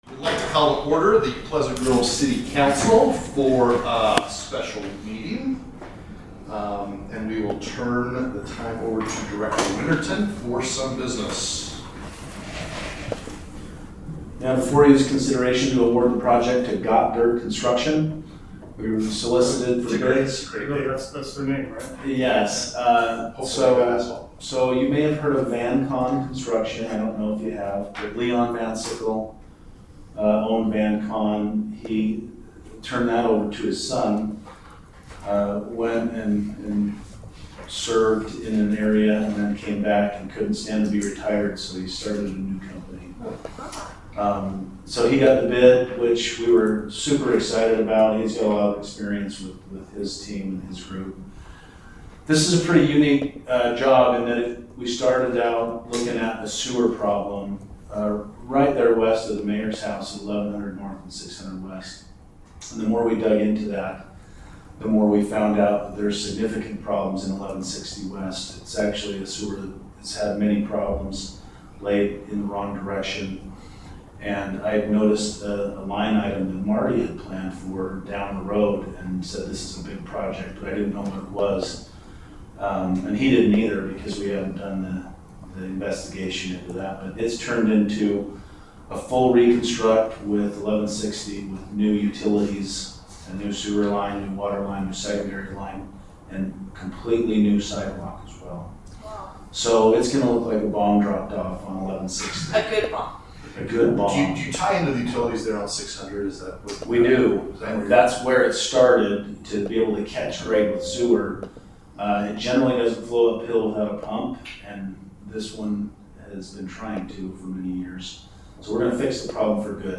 Special City Council Meeting
70 South 100 East